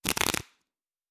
Cards Shuffle 1_06.wav